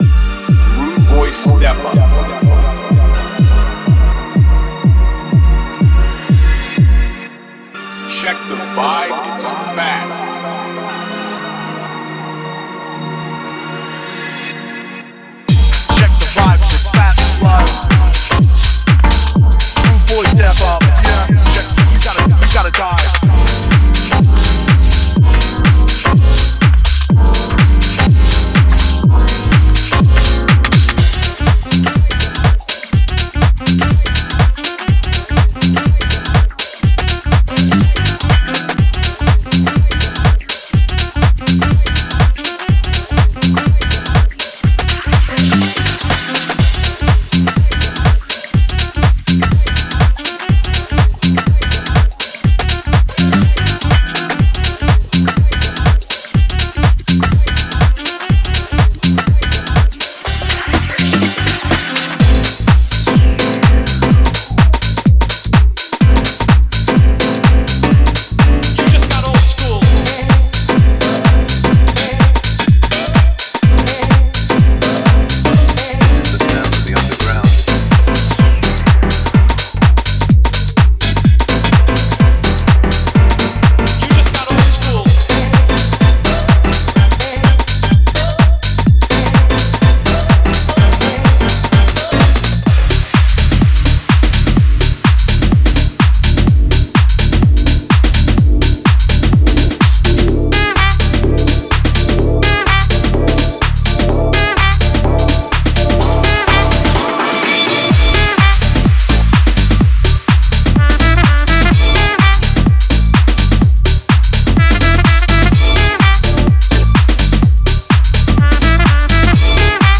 Genre:House
Bass Loops -> ヴィンテージのモノシンセなどから作られたジャッキンで容赦ない、大胆なベースループ。
Synth Loops -> ふわりと広がるパッド、リフを奏でるキー、妖艶なリードが程よいざわめきをもたらします。
FX Loops -> ウーッシーなインパクト、ダウンリフター、そしてセクシーなライザー。